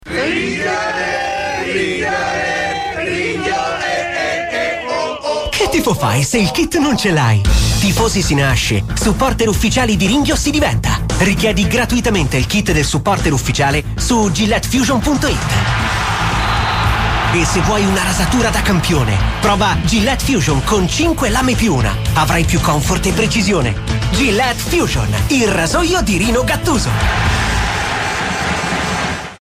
Sprecher italienisch.
Sprechprobe: eLearning (Muttersprache):